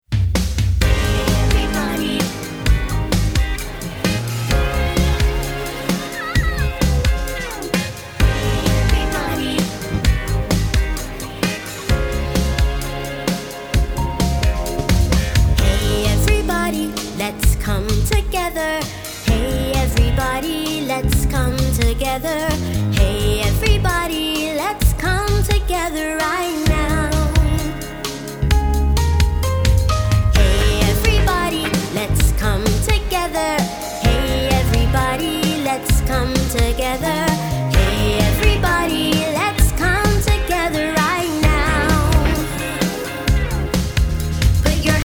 doo-wop and 80s R&B inspired
All tracks except Radio Edits include scripted dialogue.